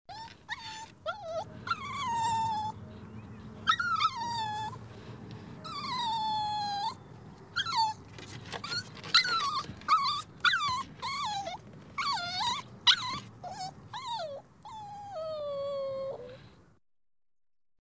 Звуки щенков
Звук пискливого щенка